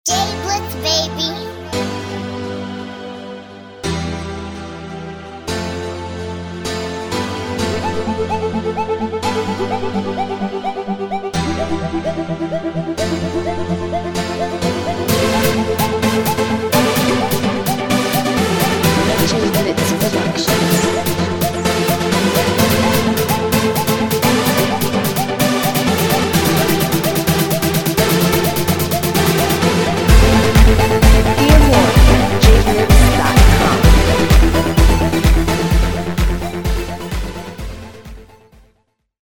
Pop Beat Instrumental